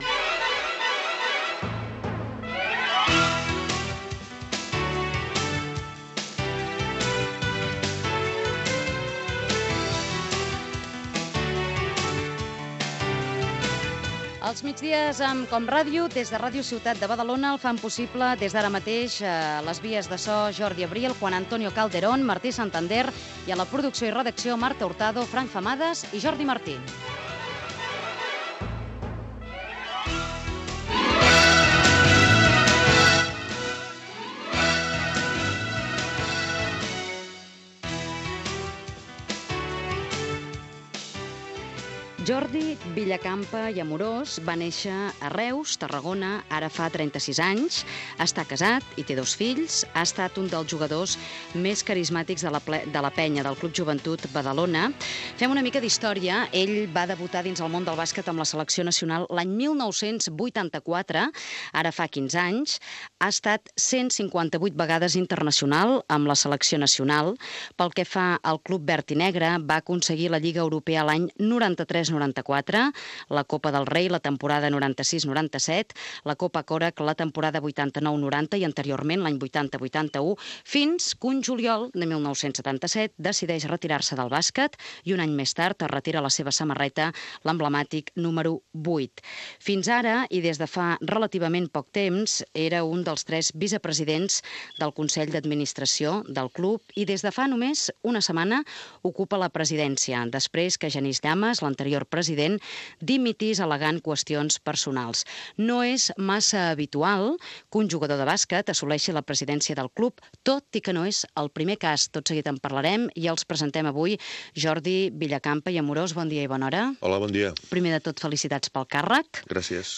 Identificació del programa, equip i fragment d'una entrevista al ex jugador del Club Joventut Badalona "La penya" i president del club Jordi Villacampa.
FM